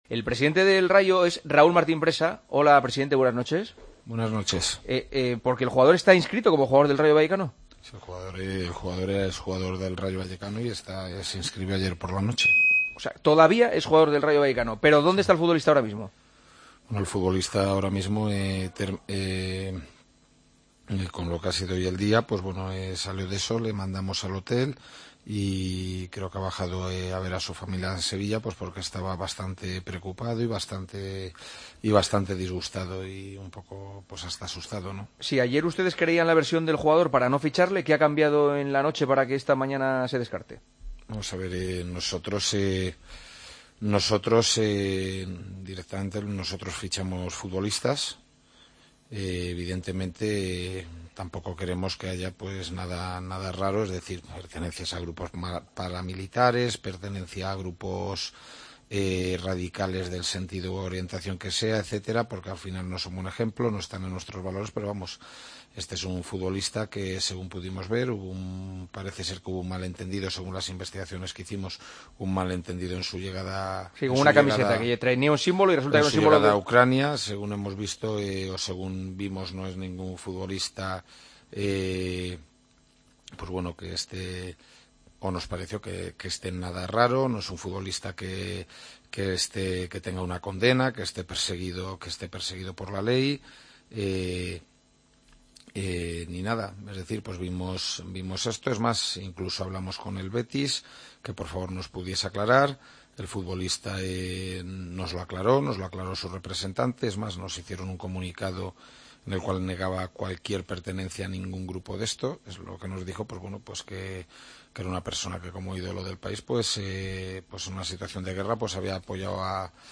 El presidente del Rayo Vallecano habló en COPE de la polémica con el fichaje de Zozulya: